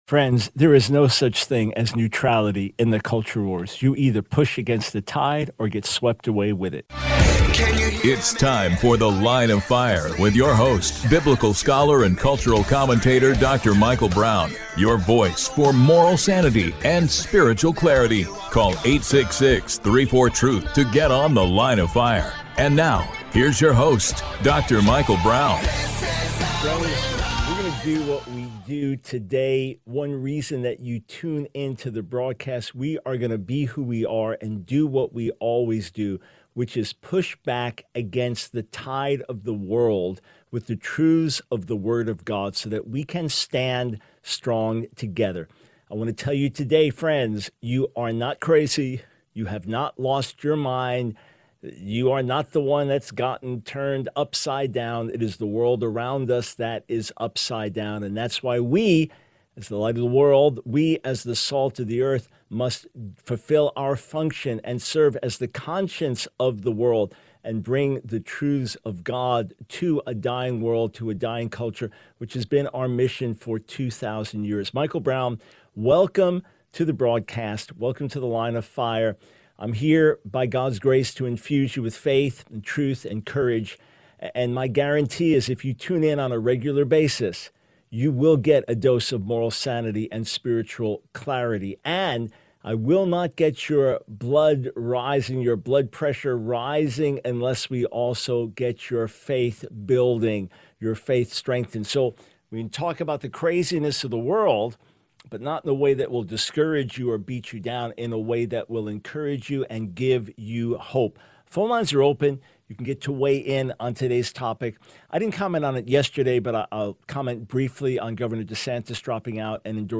The Line of Fire Radio Broadcast for 09/17/24.